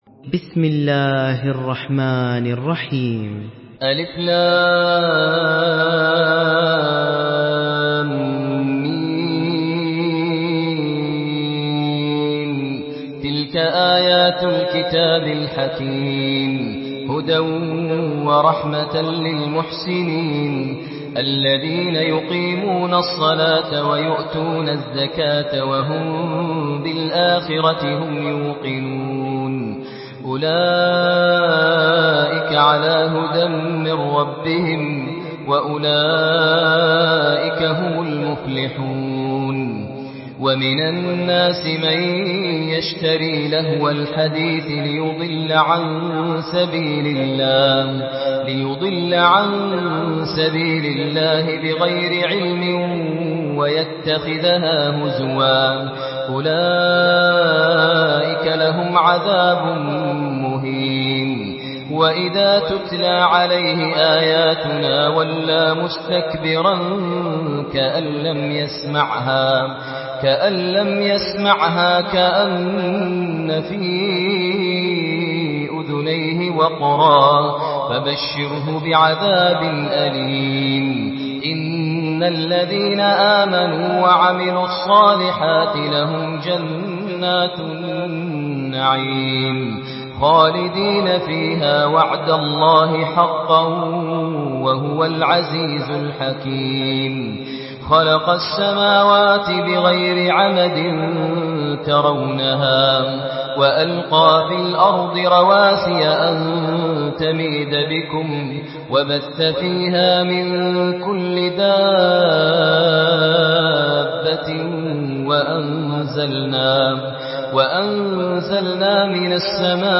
Surah لقمان MP3 by ماهر المعيقلي in حفص عن عاصم narration.
مرتل